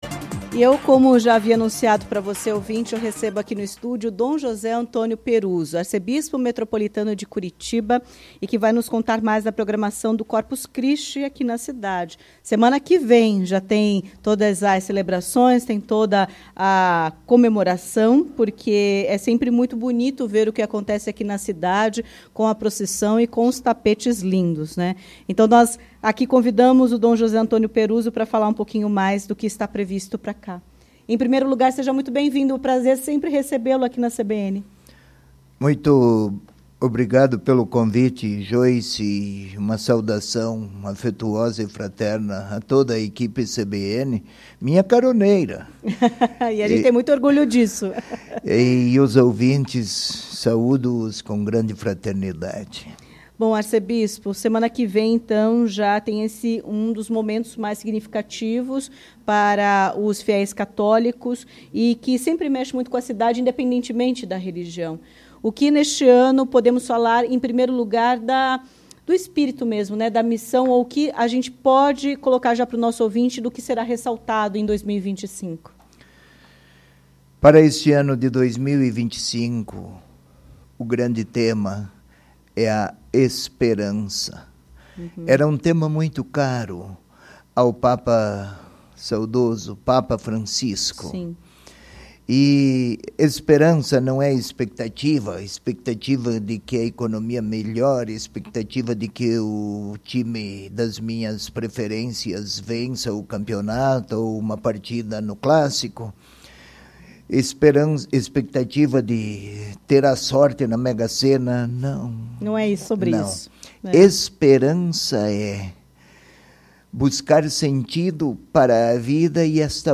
Em entrevista à CBN Curitiba, dom José Antônio Peruzzo, arcebispo metropolitano de Curitiba, destacou a esperança como tema do Corpus Christi neste ano.